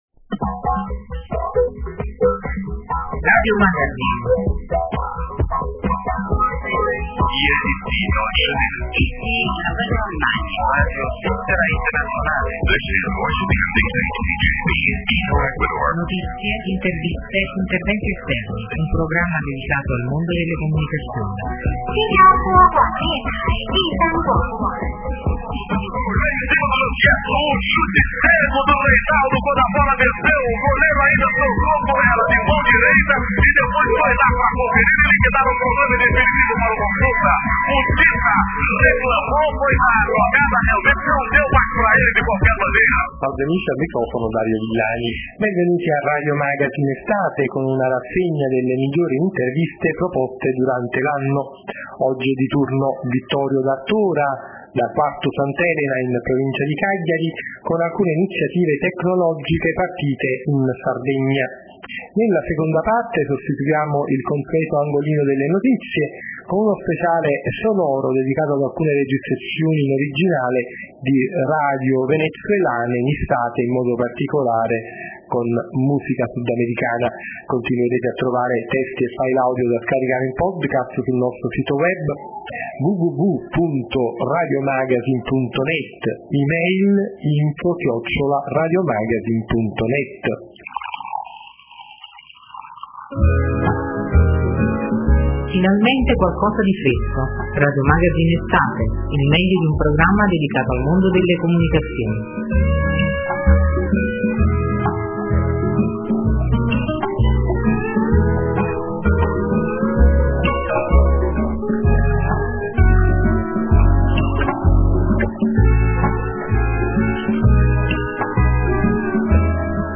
SPECIALE NATALE 2010 Puntata � speciale con registrazioni sonore da tutto il mondo, con radio di ieri e di oggi.
Segue la registrazione di Radio Algeri, anche questa storica, nacque alla vigilia dell'indipendenza dal colonialismo francese. 2^registrazione Adesso ci spostiamo in Oceania, con la registrazione dello splendido carillon, quasi in disuso di Radio Australia.